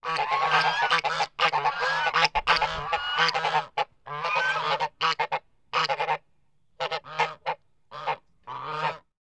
GEESE 1-L.wav